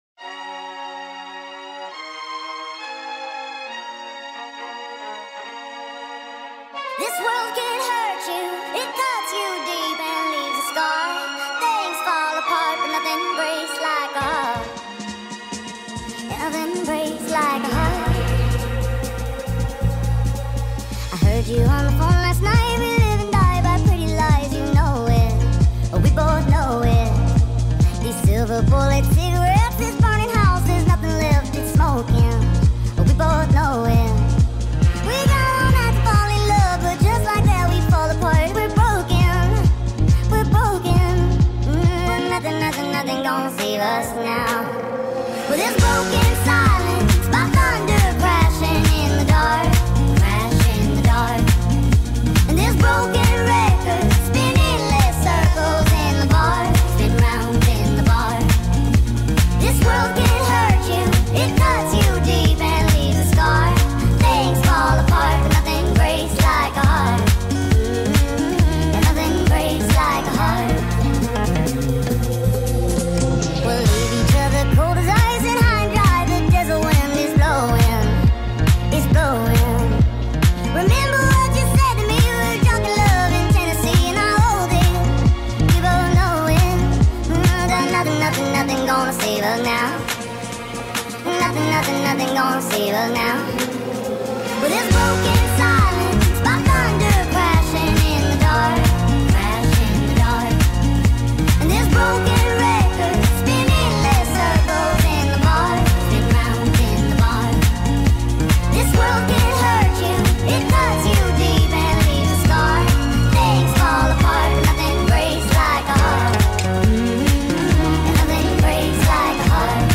غمگین
غمگین خارجی